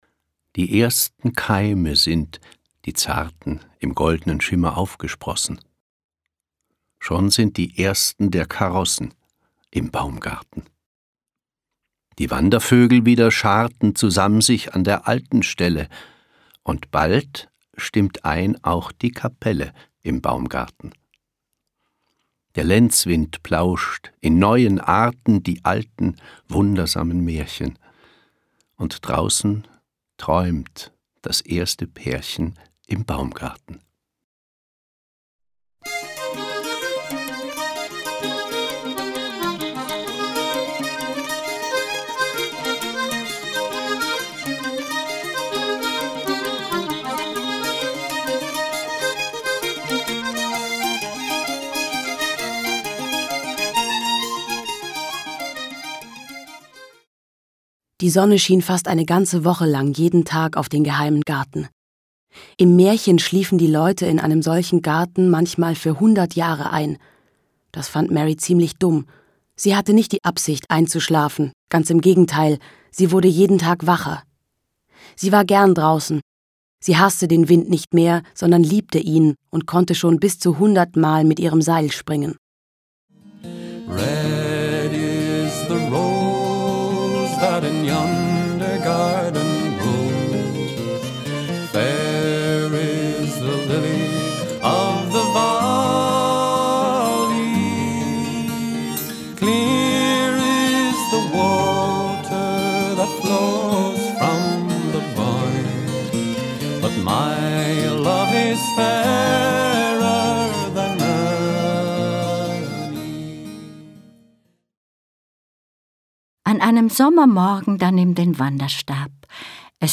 Geschichten, Gedichte und Lieder